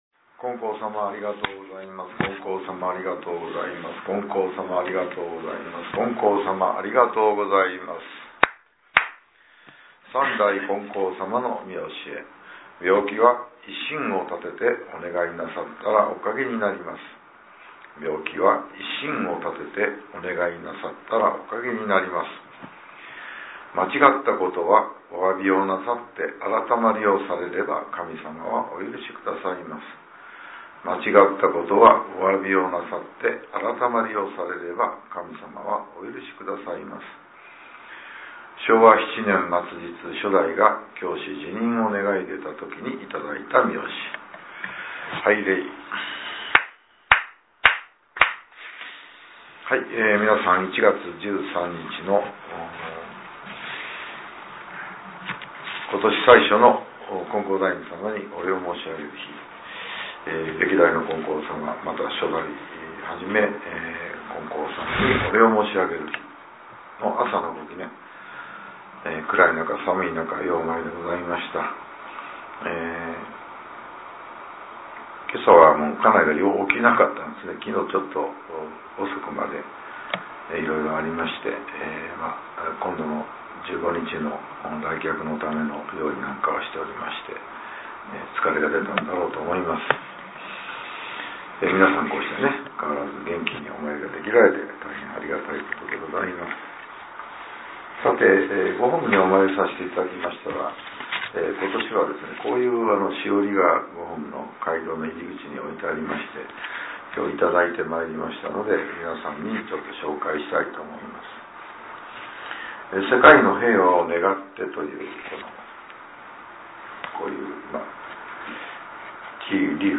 ラジオ放送より（音声ブログ） | 悩み相談・願い事祈願「こころの宮」